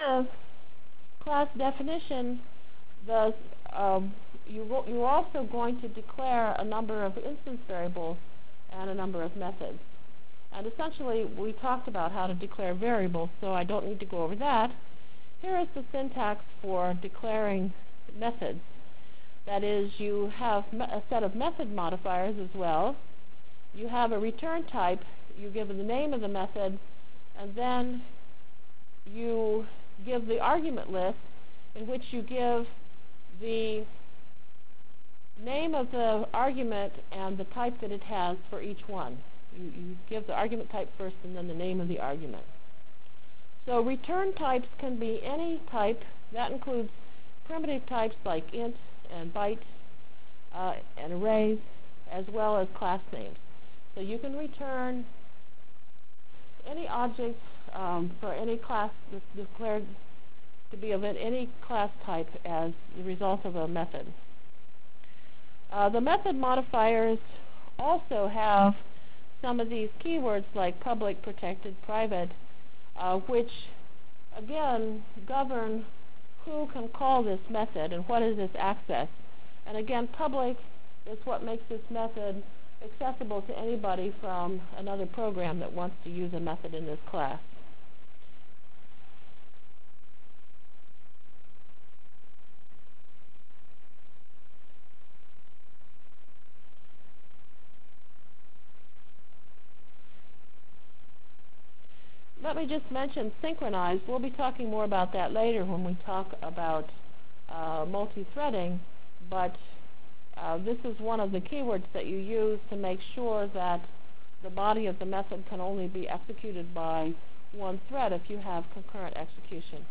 Delivered Lecture